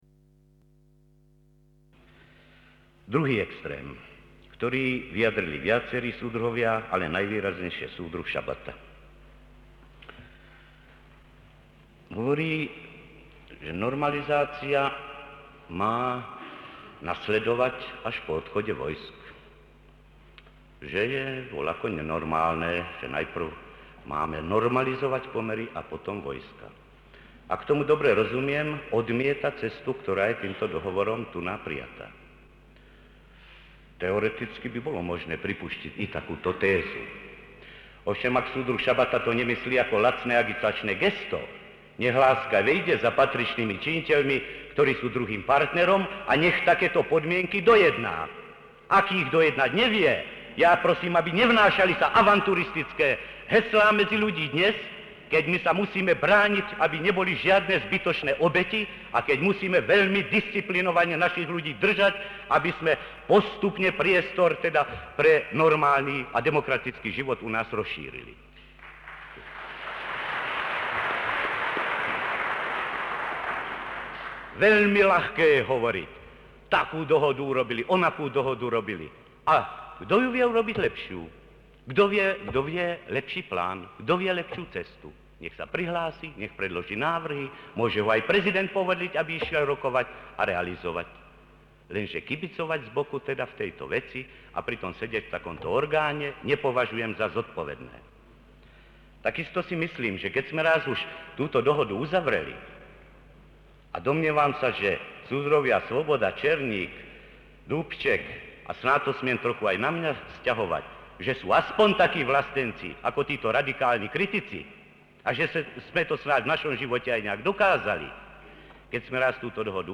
Autentický záznam – Vystoupení G. Husáka (délka celého projevu: 23 min. 10 s.), kritika nedostatků polednového vývoje 1968, kritika názorů diskutujících i postojů v Předsednictvu ÚV KSČ, obhajoba nástupu konsolidace a normalizace po srpnovém vpádu vojsk a podepsání tzv. moskevských protokolů, o vůli Slováků setrvat v společné republice, k antisocialistickým silám ve společnosti.
1968, 31. srpen, Praha, Pražský hrad, Španělský sál.